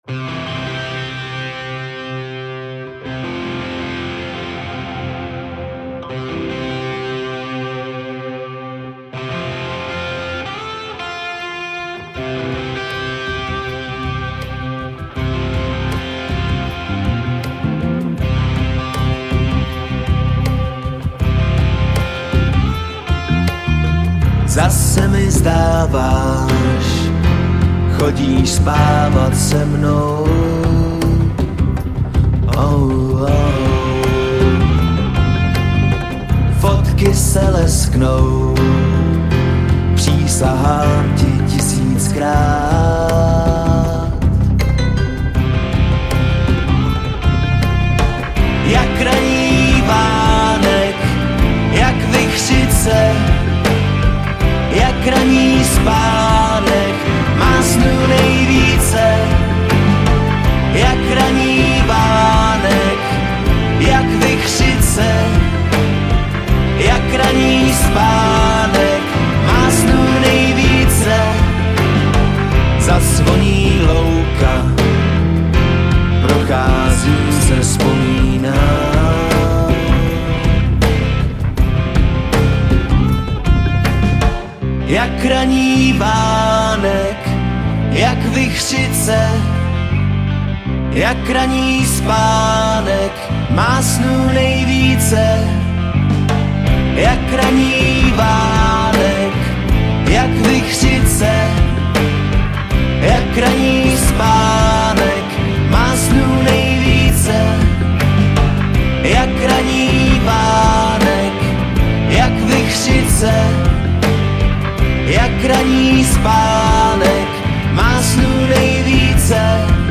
Žánr: Pop
veselý nekomplikovaný pop rock